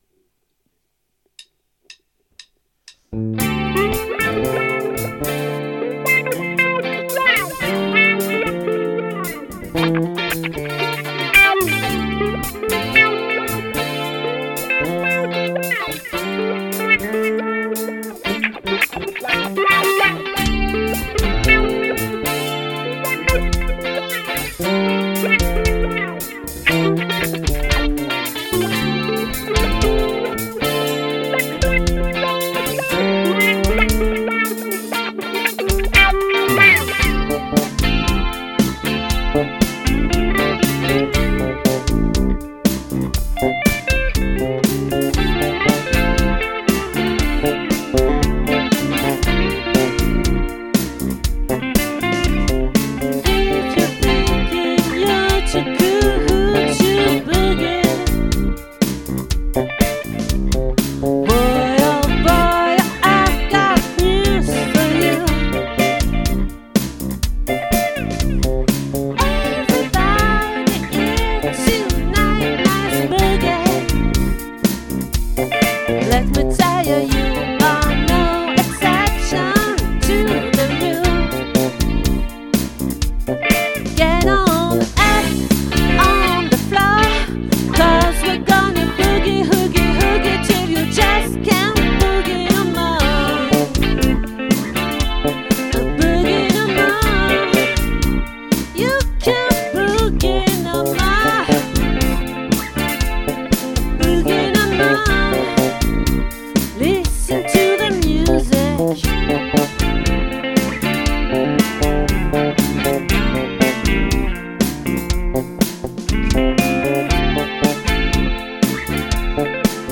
🏠 Accueil Repetitions Records_2022_02_17